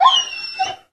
bdog_hurt_1.ogg